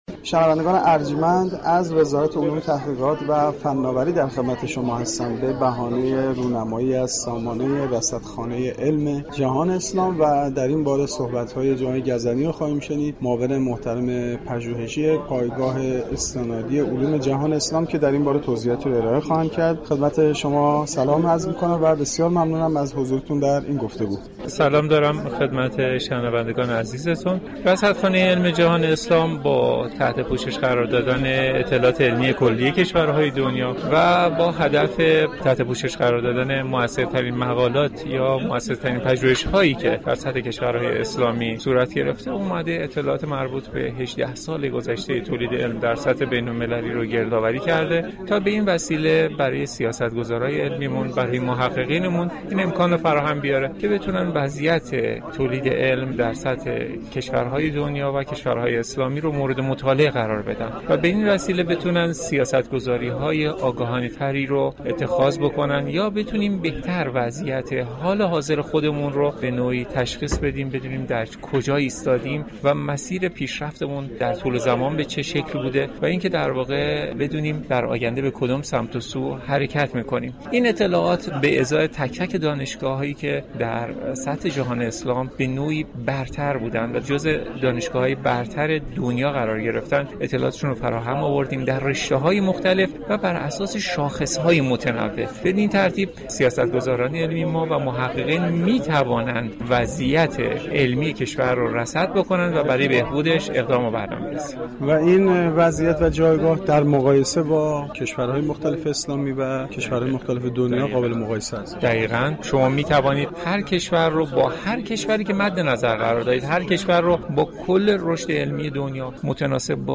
در گفتگو با سایت رادیو فرهنگ